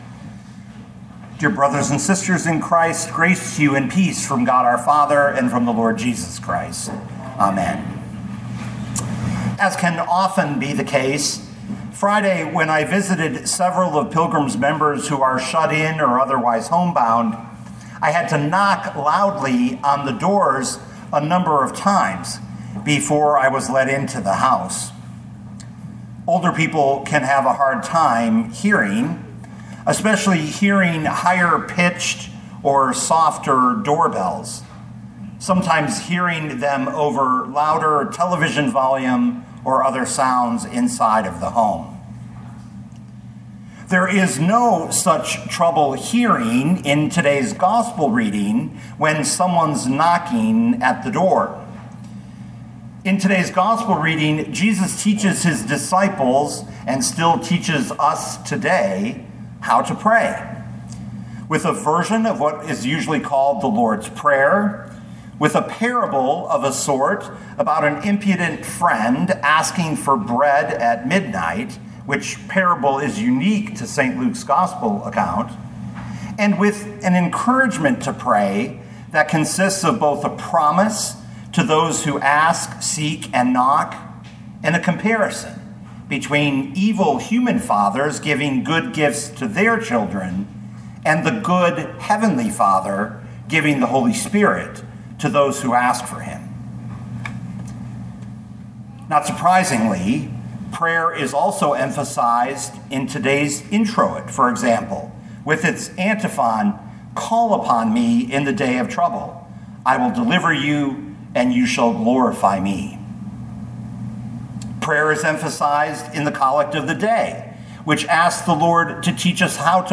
2025 Luke 11:1-13 Listen to the sermon with the player below, or, download the audio.